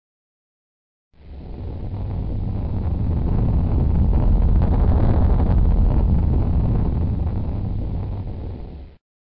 Earthquake 3 sound effect
Category: Sound FX   Right: Personal